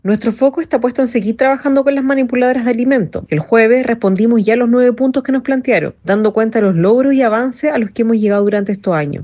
La directora nacional de Junaeb, Camila Rubio, evitó polemizar y afirmó a Radio Bío Bío que se ha invitado a las dirigentes al diálogo, con el fin de mejorar las condiciones que deben otorgar las empresas.